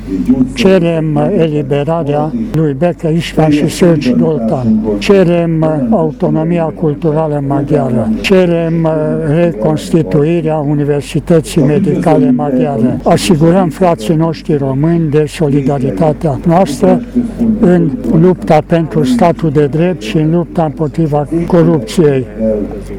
În cadrul evenimentelor organizate la Târgu Mureș de Ziua Libertății Secuilor, participanții au cerut eliberarea celor doi din închisoare.
Preşedintele Consiliului Naţional Maghiar din Transilvania, Tökés László: